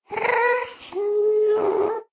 moan6.ogg